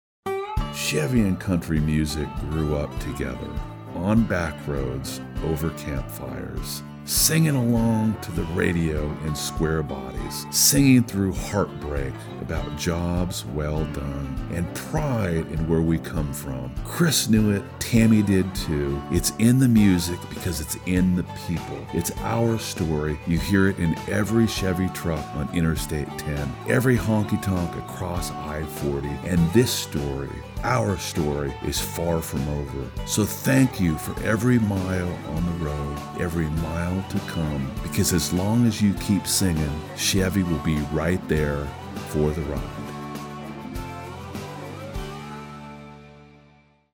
talented male voiceover artist known for his warm, rich tone that captivates listeners